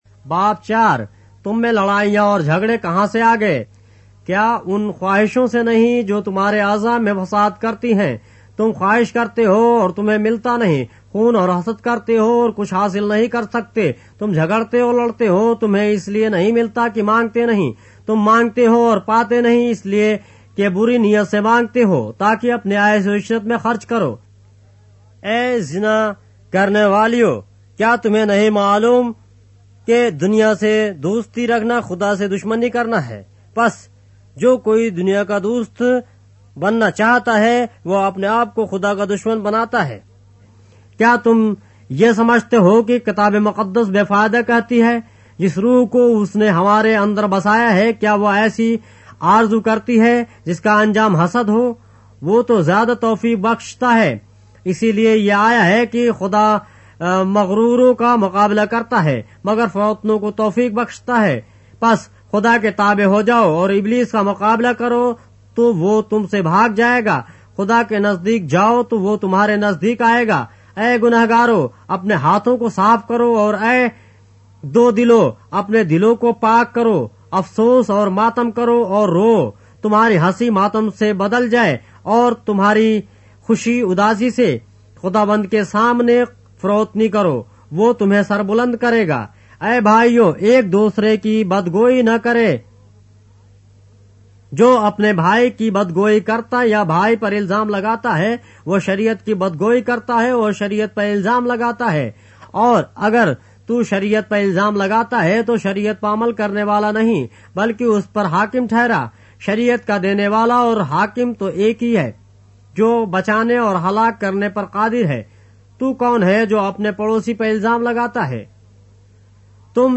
اردو بائبل کے باب - آڈیو روایت کے ساتھ - James, chapter 4 of the Holy Bible in Urdu